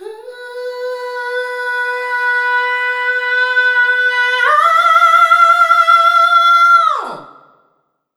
SCREAM 3  -R.wav